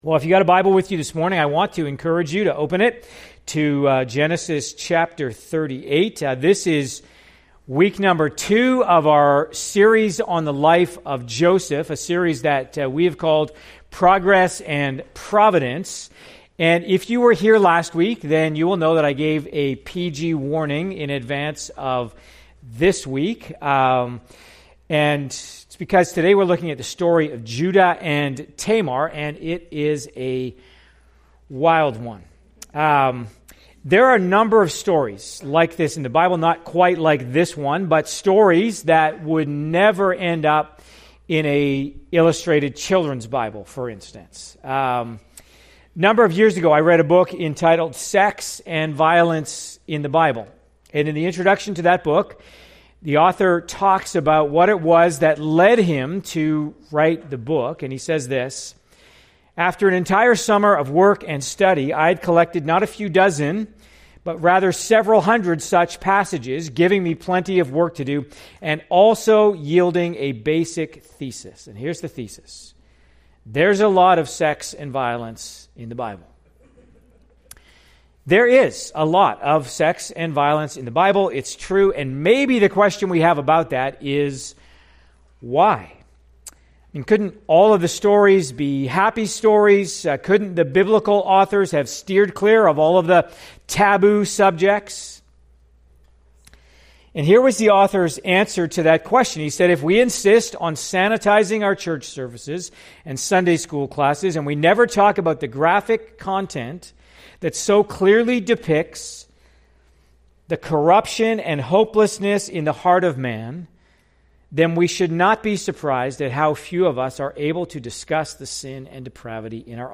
PG NOTICE: This sermon contains subject matter that may not be appropriate for children.